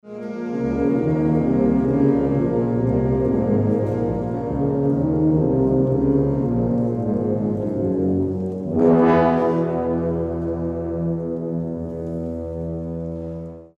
Audiobeispiel eines Tubensatzes
Audiobeispiel Tubensatz
tubensatz.mp3